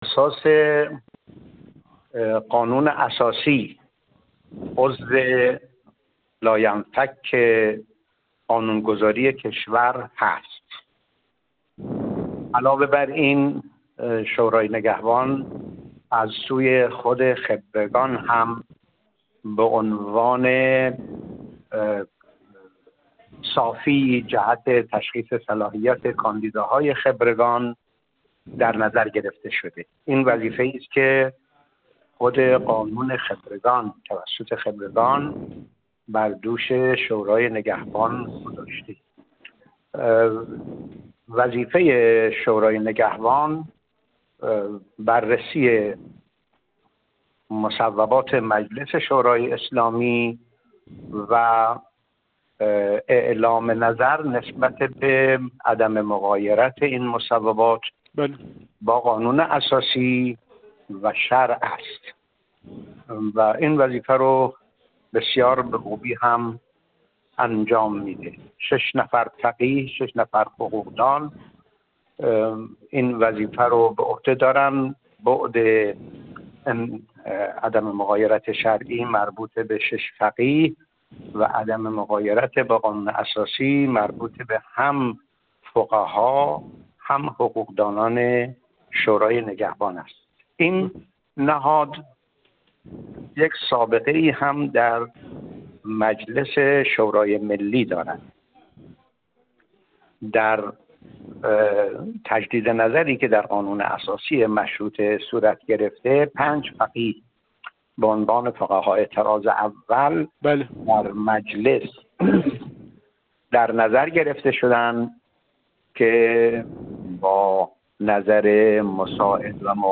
حجت‌الاسلام والمسلمین مصباحی مقدم، عضو مجمع تشخیص مصلحت نظام
گفت‌وگو